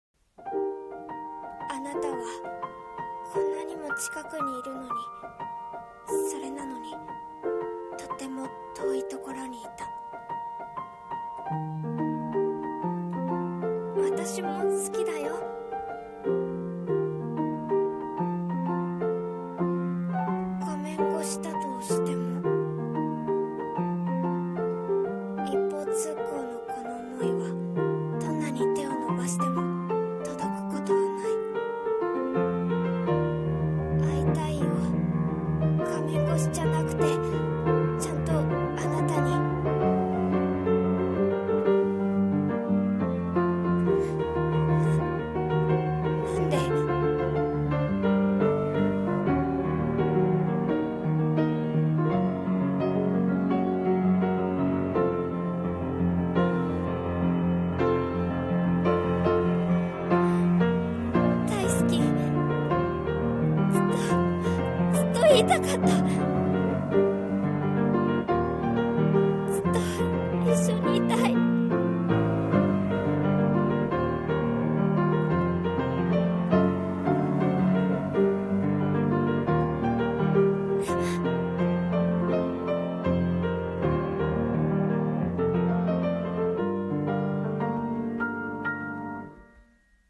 【声劇台本】遠くて近い君に